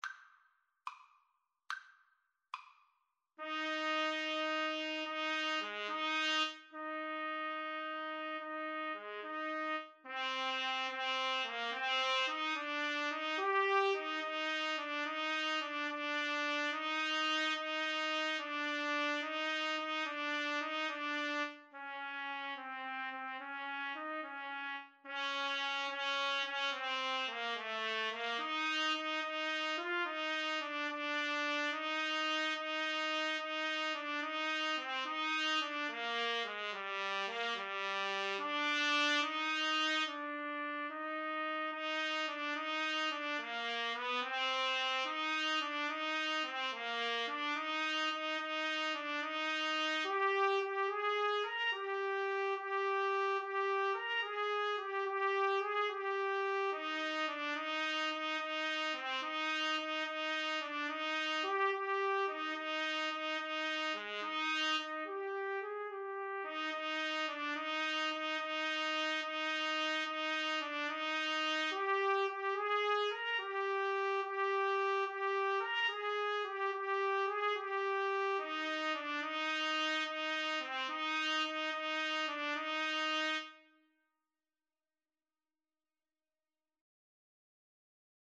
6/8 (View more 6/8 Music)
Maestoso . = c. 72